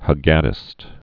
(hə-gädĭst, -gô-)